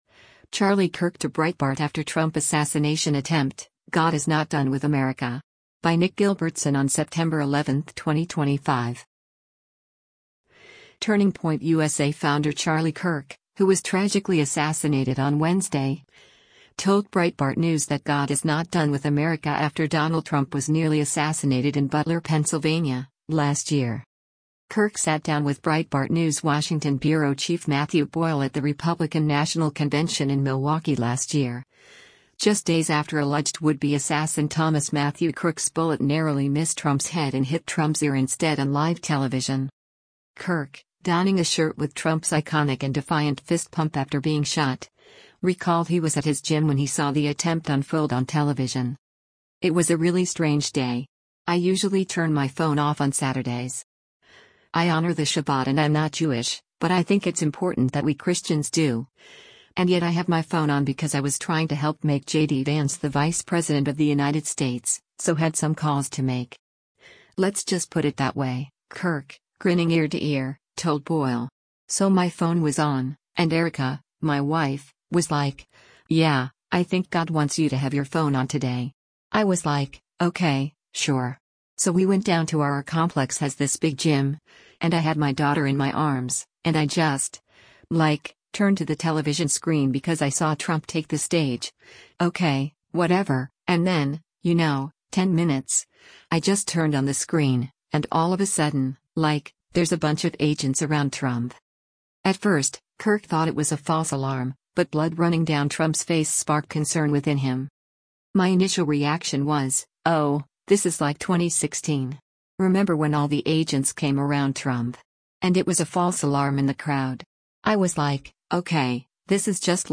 at the Republican National Convention in Milwaukee